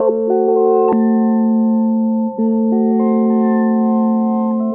WarpedGtr1_101_C.wav